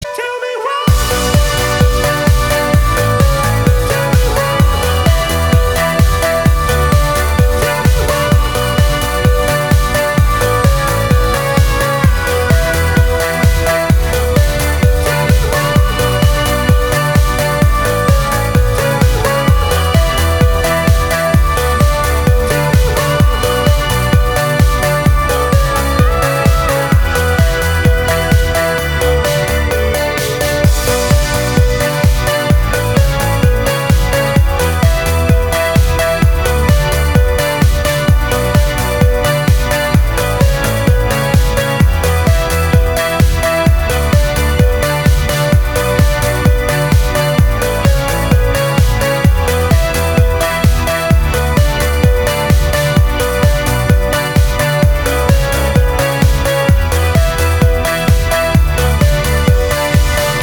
• Качество: 320, Stereo
dance
Electronic
EDM
Trance
house